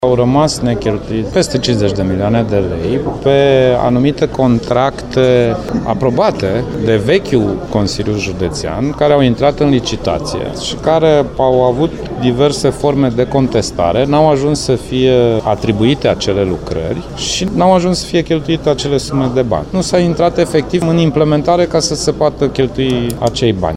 Este vorba despre tronsoanele Drăgşineşti – Fârdea, Iohanisfeld – Ivanda, Babşa – Ghizela, Faget – Drăgşineşti, Şipet – Folea, Maşloc – Charlottenburg şi Hitiaş – Bacova. Fondurile sunt asigurate din împrumutul de 200 de milioane de lei contractat de Consiliul Judeţean Timiş în 2014 si care a fost prelungit până la sfârșitul anului viitor, spune președintele CJ Timiș, Sorin Grindeanu: